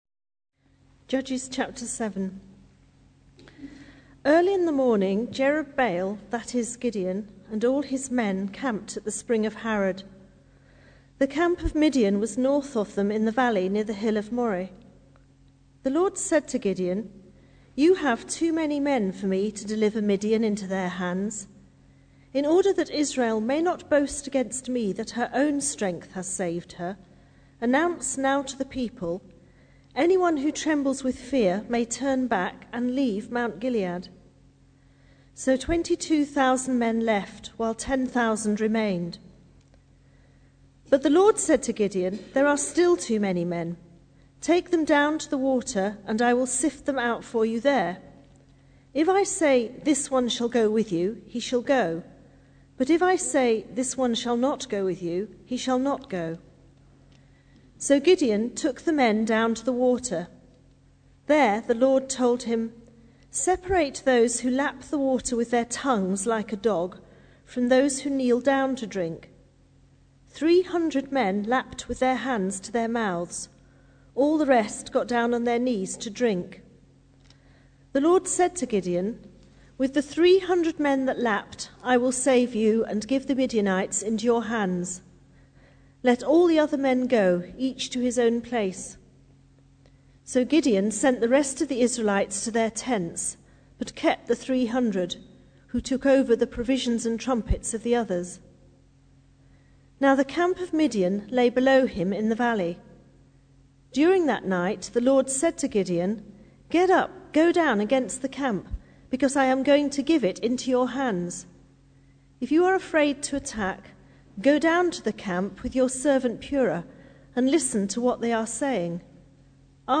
Judges 7 Service Type: Sunday Evening Bible Text